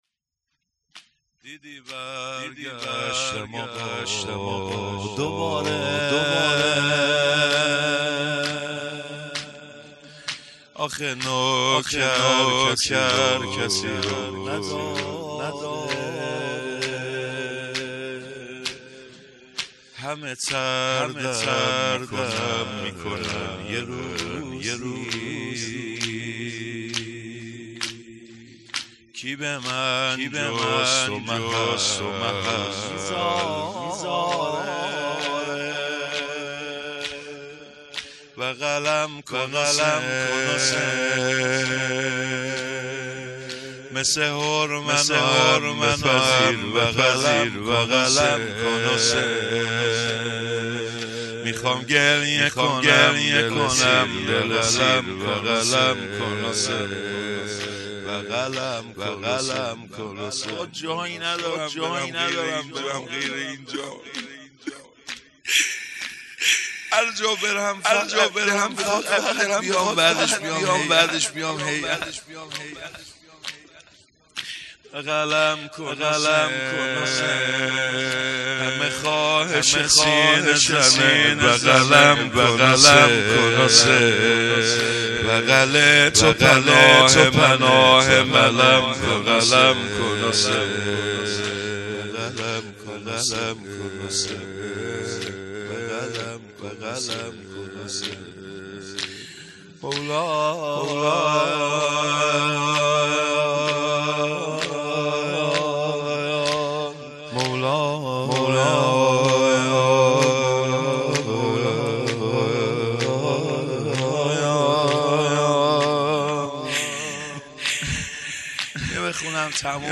مراسم شهادت حضرت فاطمه زهرا سلام الله علیها آبان ۱۴۰۳